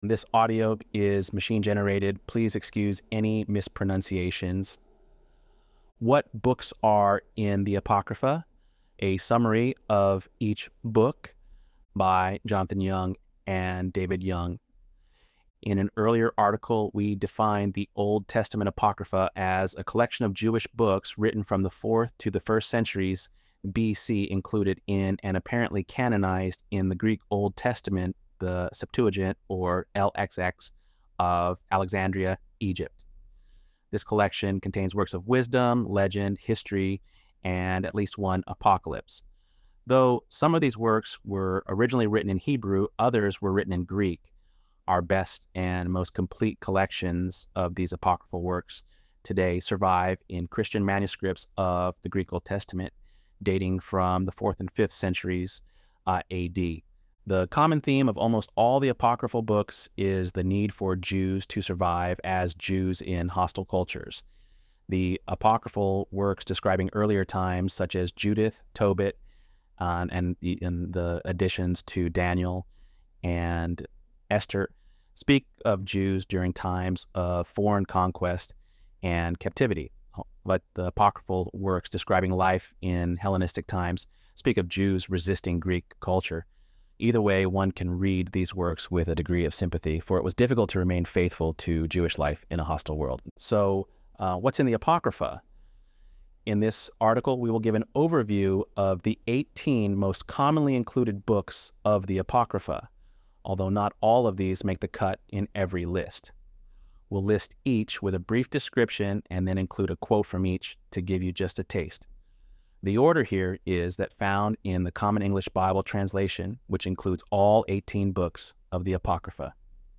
ElevenLabs_8.6_summary-1.mp3